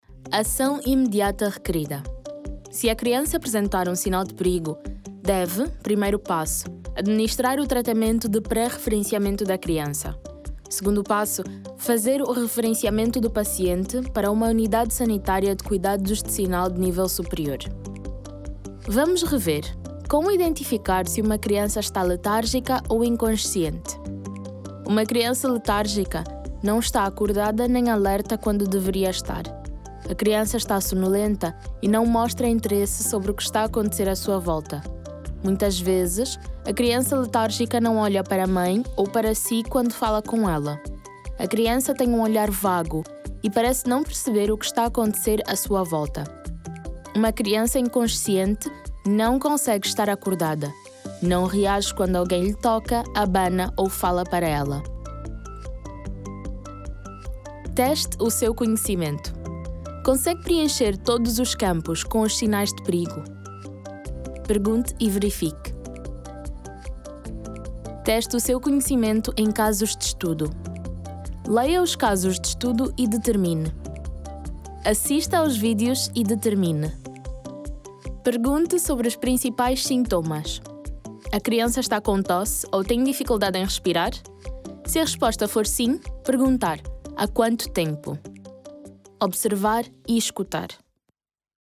My voice style is natural and conversational, with a neutral accent. My voice is very warm, youthful, expressive and extremely professional, understanding each briefing in a unique way....
1122DEMO_ELEARNING_PT.mp3